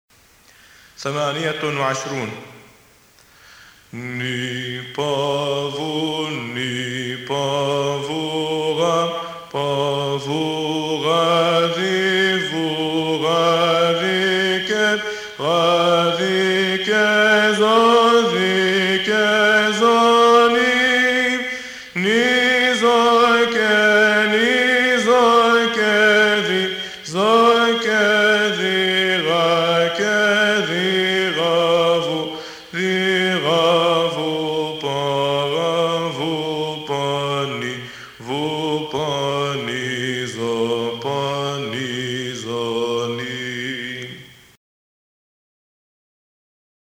تمارين مبادئ الموسيقى البيزنطية